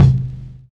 VINYL 7 BD.wav